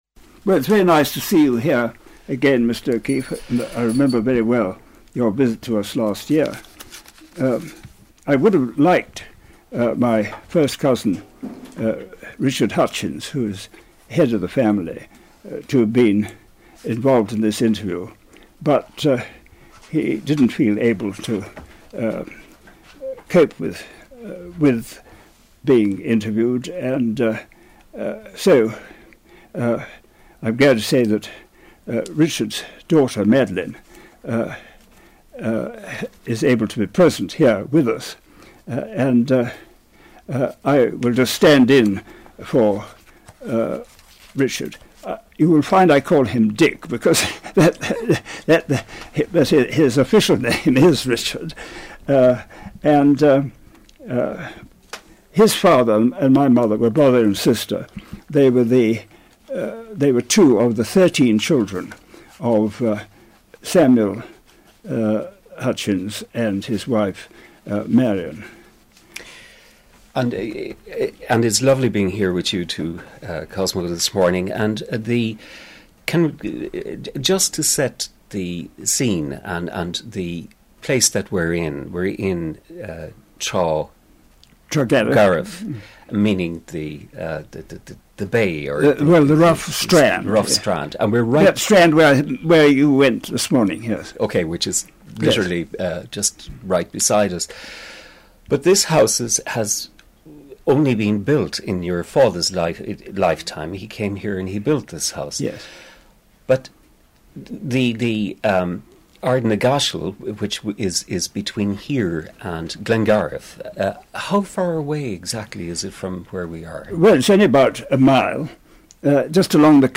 Interview
hutchins-cosmo-interview-01.mp3